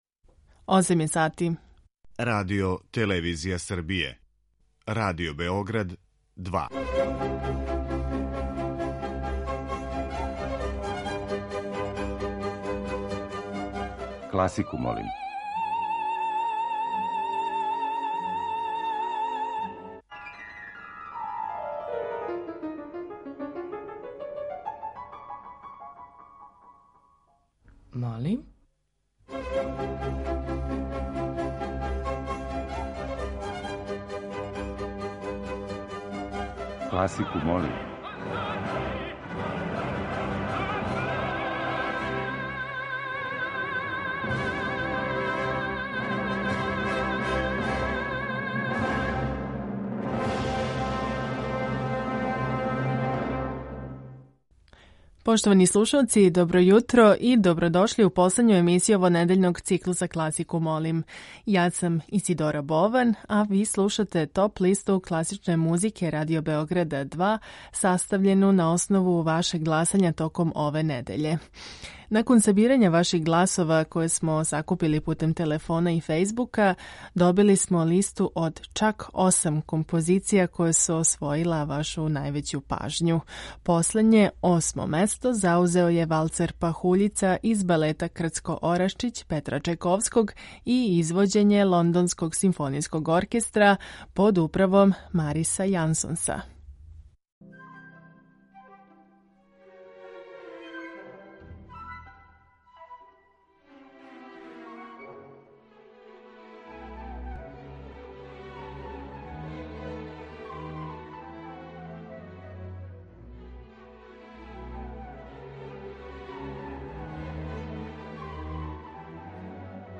Барокне свите